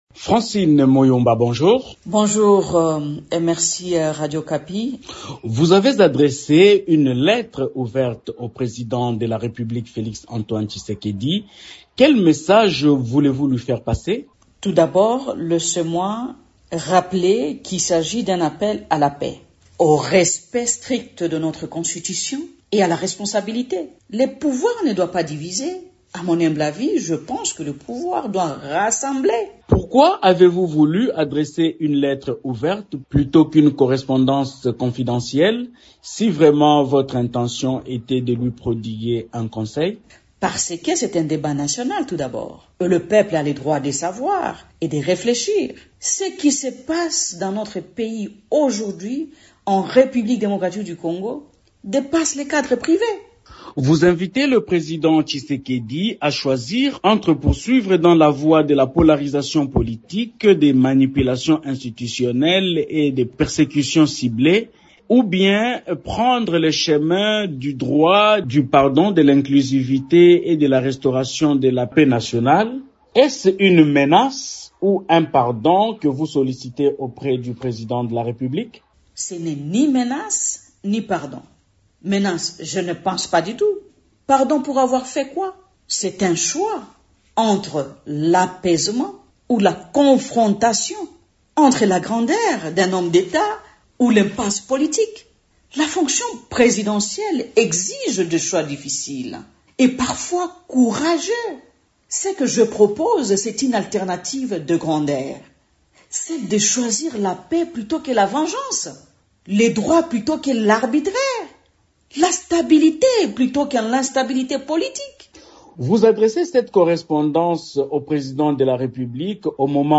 Invitée de Radio Okapi ce mardi 20 mai, Francine Muyumba, affirme que sa démarche est un appel à la paix et non un calcul politicien.